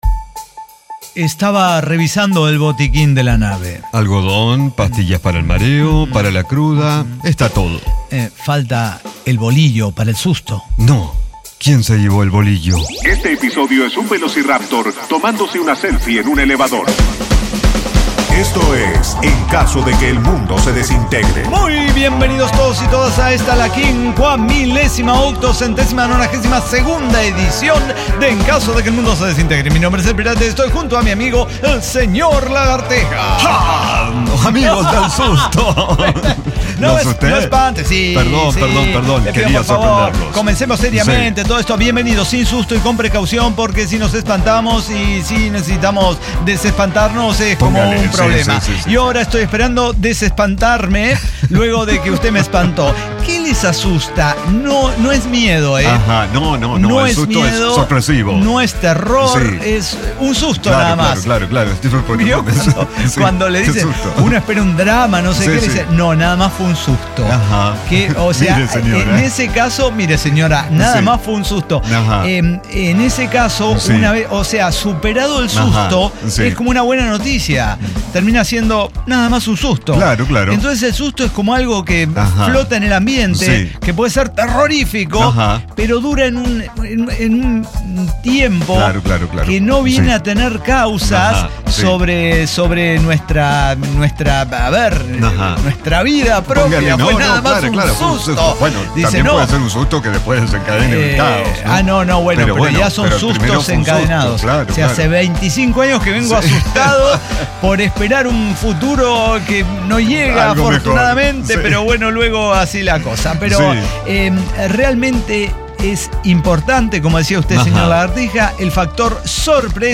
El Cyber Talk Show
Diseño, guionado, música, edición y voces son de nuestra completa intervención humana.